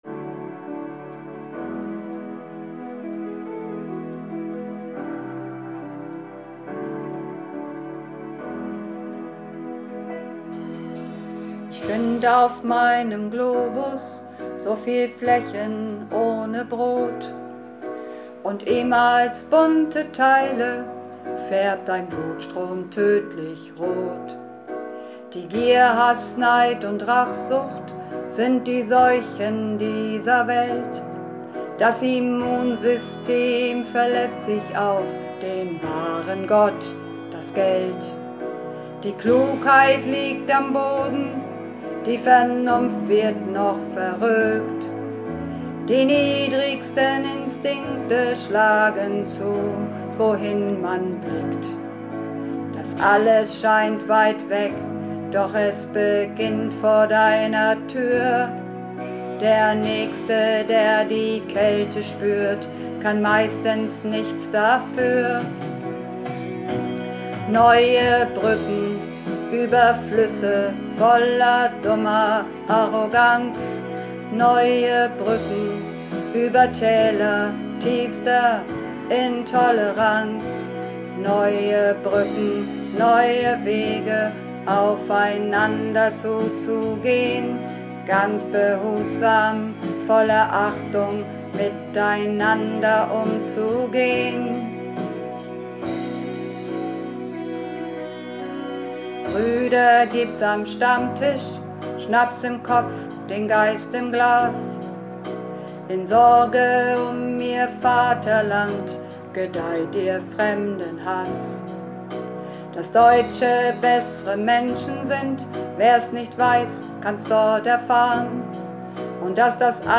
Runterladen (Mit rechter Maustaste anklicken, Menübefehl auswählen)   Neue Brücken (Bass)
Neue_Bruecken__2_Bass.mp3